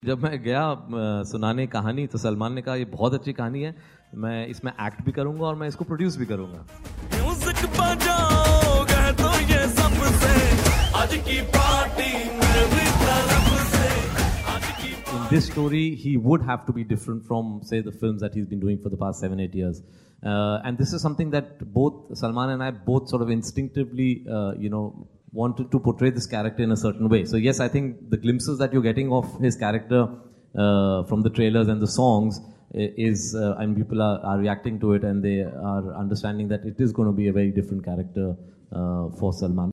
फ़िल्म 'बजरंगी भाईजान' के निर्देशक कबीर ख़ान बताते हैं कि आख़िर सलमान ख़ान ने क्यों बनाई बजरंगी.